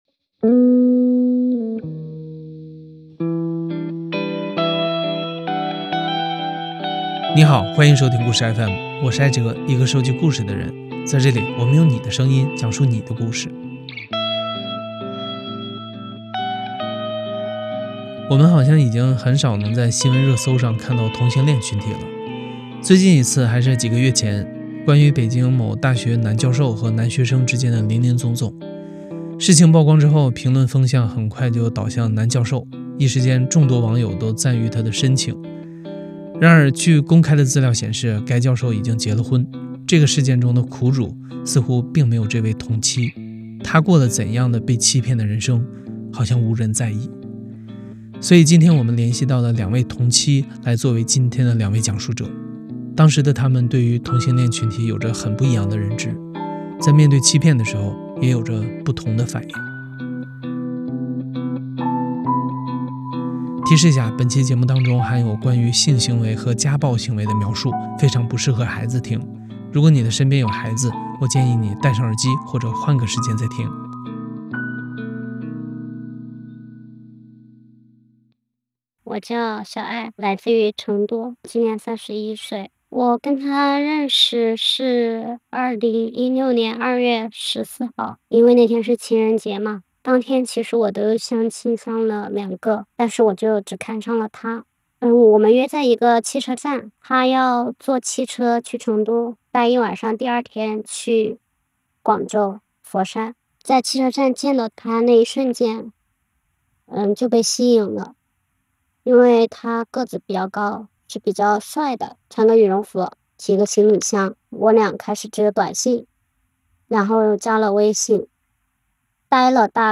因此我们联系到两位同妻来作为今天的两位讲述者，当时的她们对于这个群体有着不同的认知，在面对欺骗时，也有着不同的反应。
故事FM 是一档亲历者自述的声音节目。